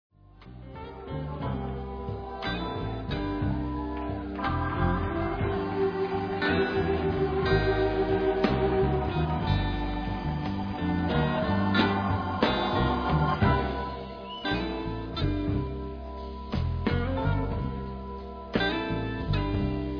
Southern (jižanský) rock